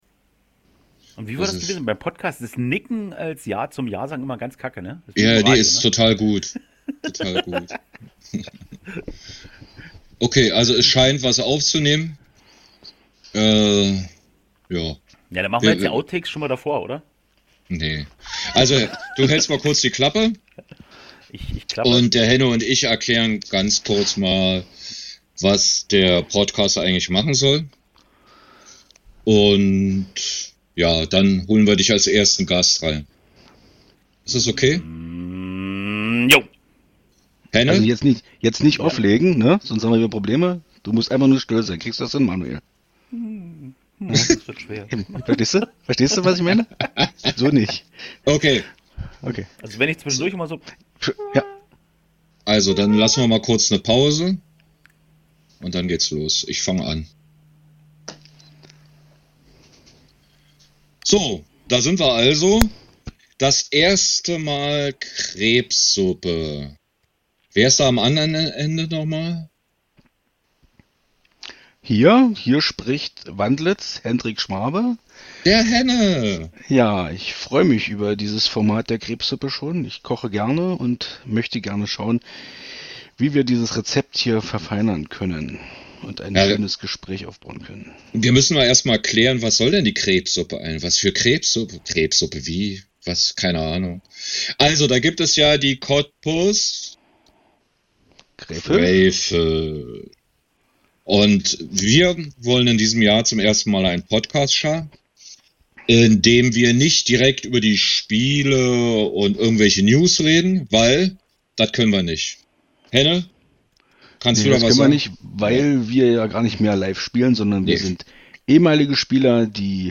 Nicht richtig professionell aber mit Freude ;-)